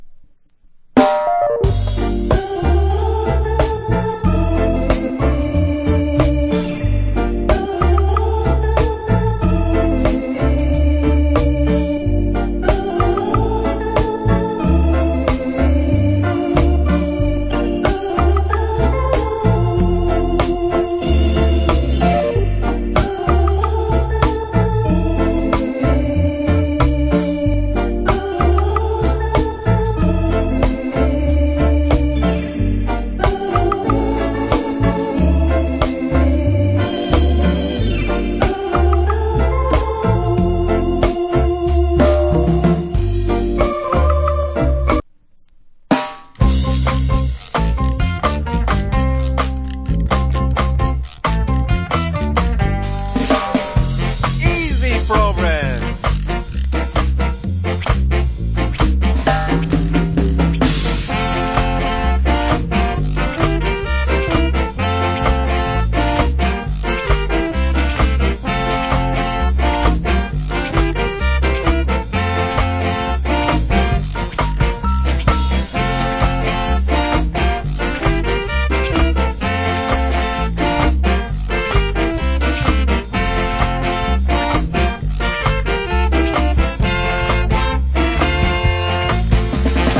category Ska
Cool Rock Steady Inst. / Nice Ska Inst.